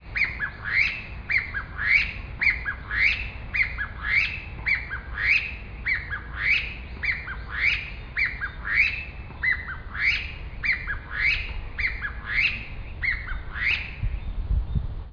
Apparently, it was a bit of a coup–when I later tracked the birds to a more inward location in Sengenyama Park, they started making rather loud calls (
But he did tell me that the calls were very distinctive, and that this was the time for the birds to be pairing–which explains the pair: